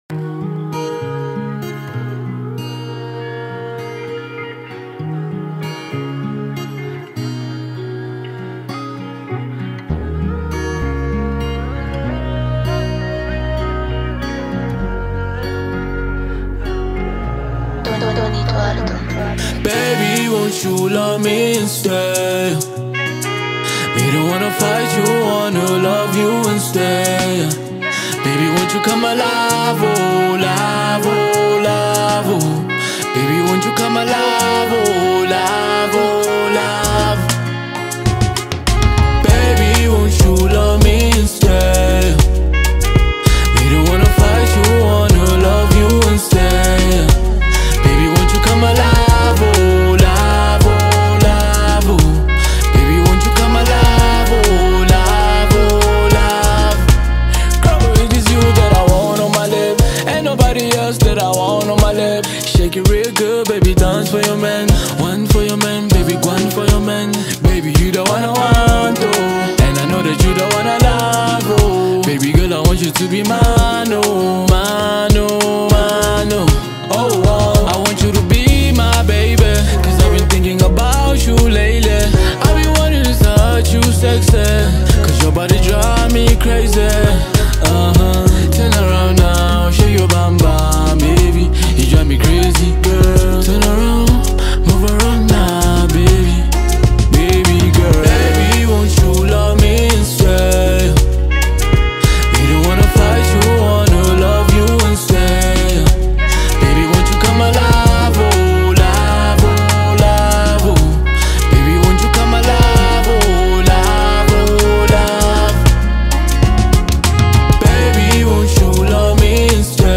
Afropop
afrofusion